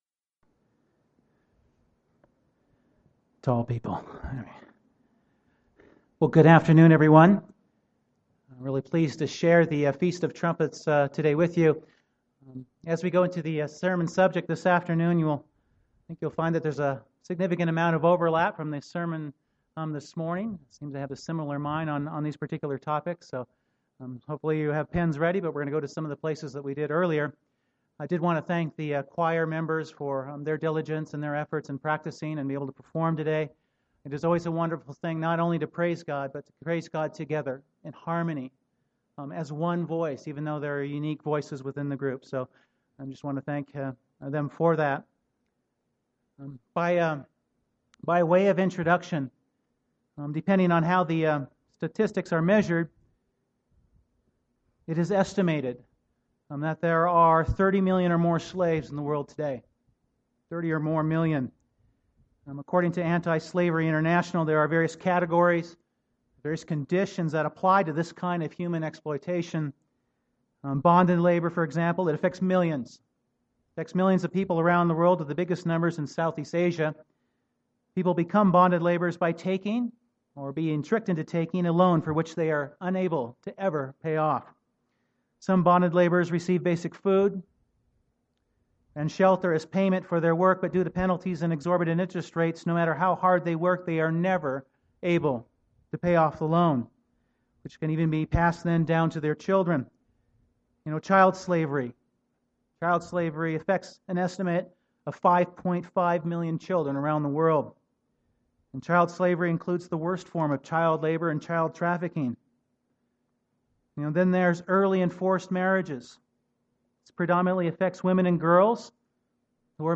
This sermon focuses on the hope of God’s promise to save a remnant of the descendants of Jacob during what is described as the most difficult of times to come – the great tribulation.
Given in Denver, CO